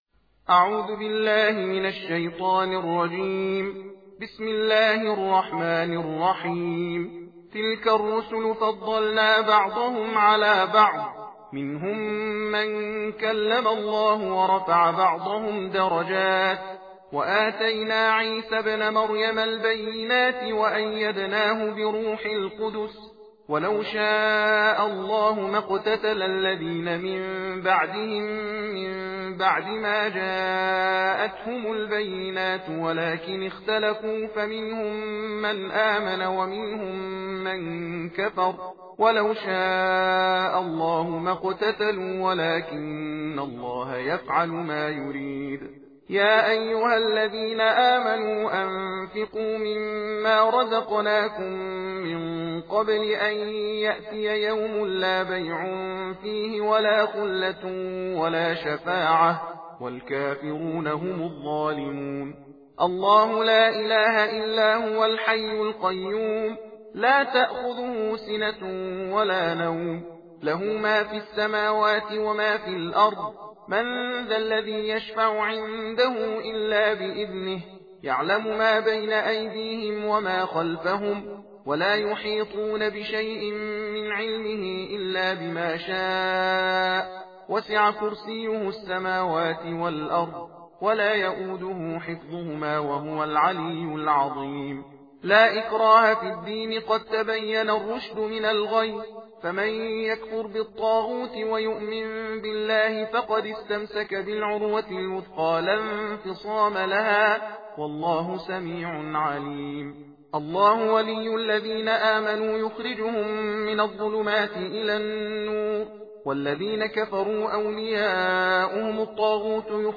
صوت/ تندخوانی جزء سوم قرآن کریم